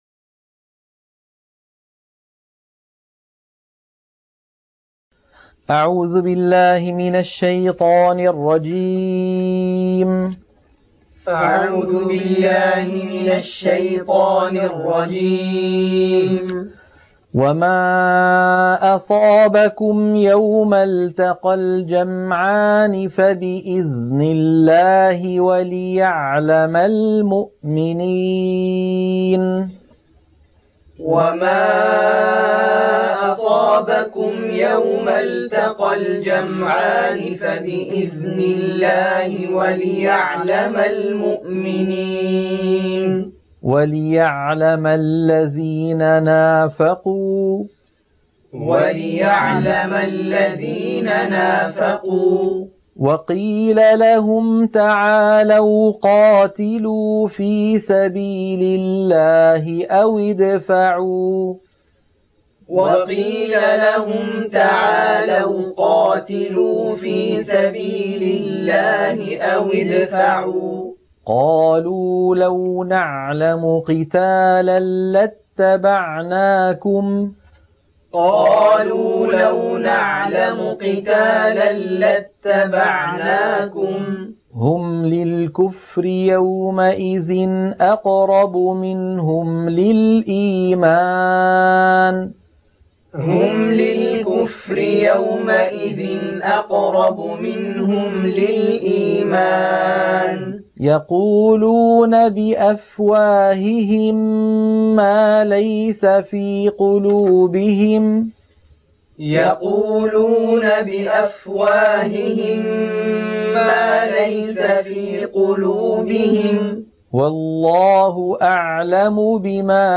عنوان المادة تلقين سورة آل عمران - الصفحة 72 التلاوة المنهجية